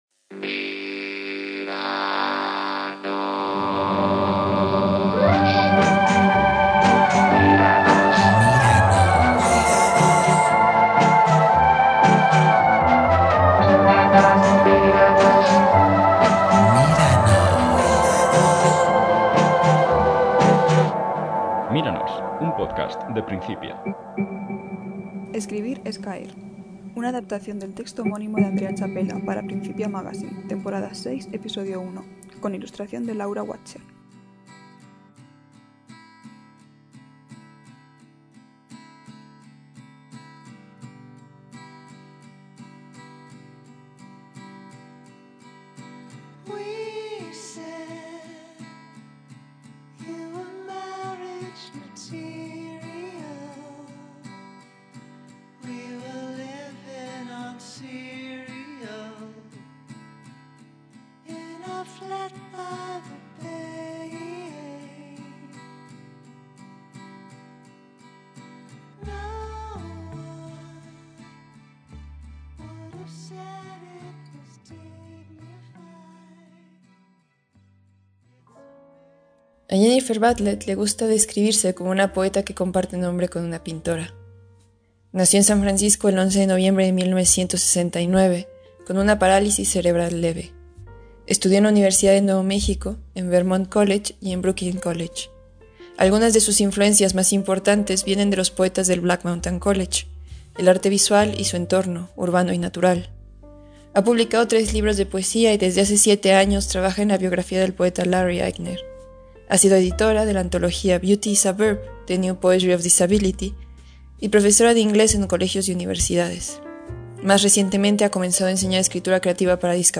Es la ficción sonora producto de la conversión de Principia Magazine Temporada 6 Episodio 1.